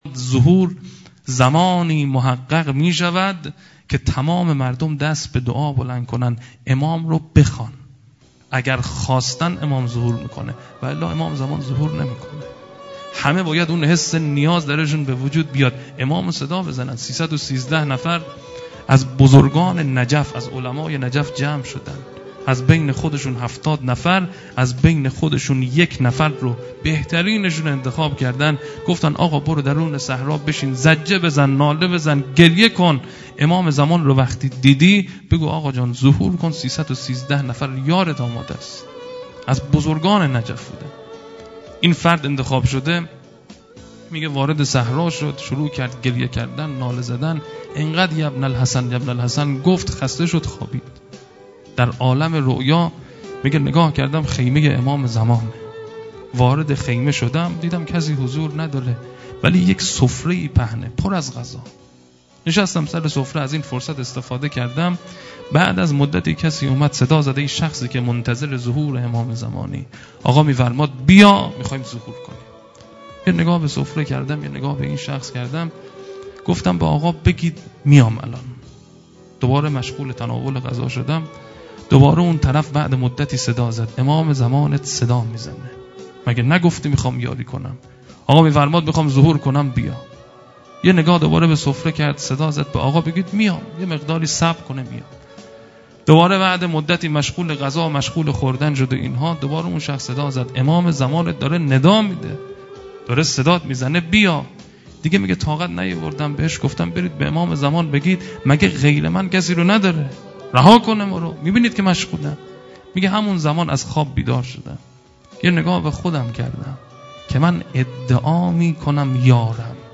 ۲ سخنران